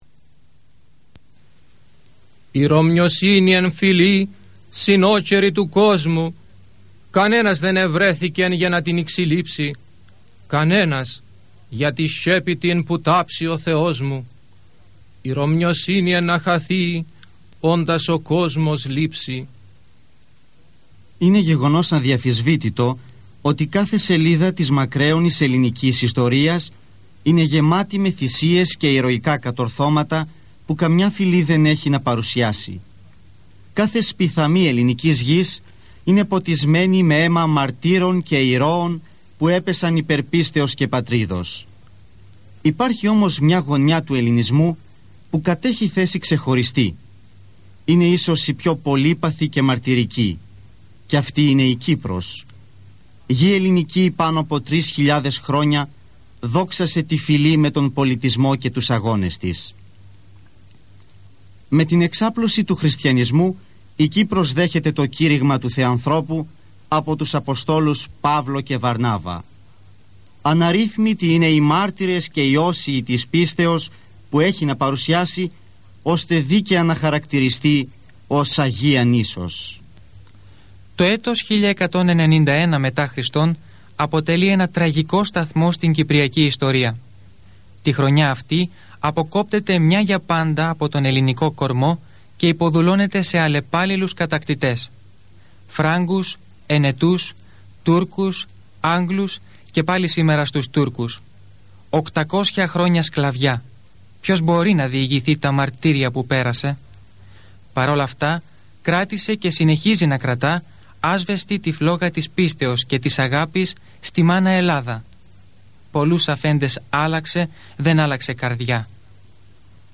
Κείμενα-και-τραγούδια-ενορχηστρωμένο.mp3